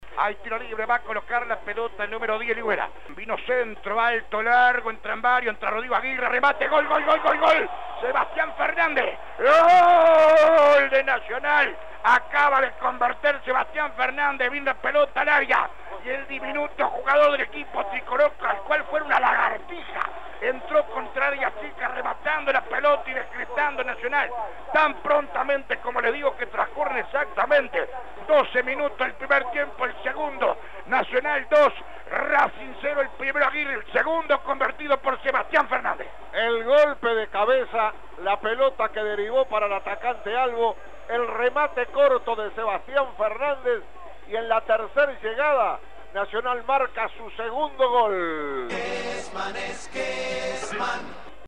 Todo comenzó con un golazo de Rodrigo Aguirre que así relato Alberto Kesman: